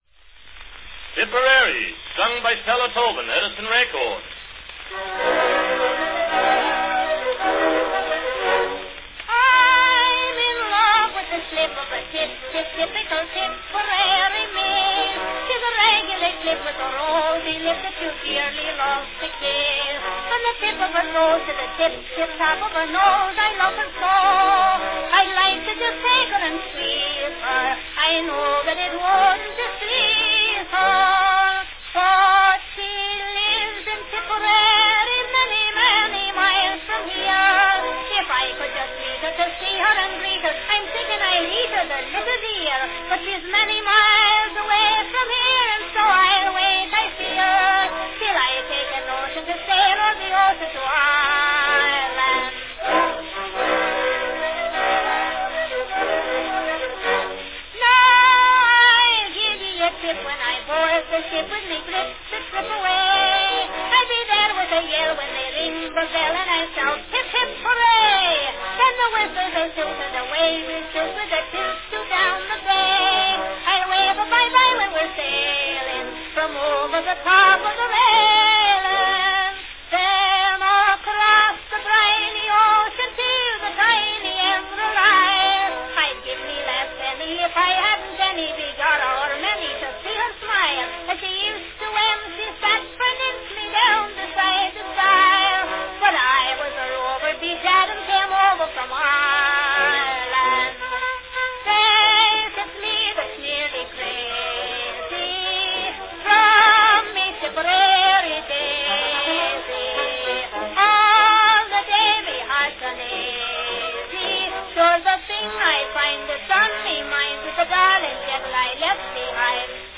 Category Irish March Song
Orchestra accompaniment.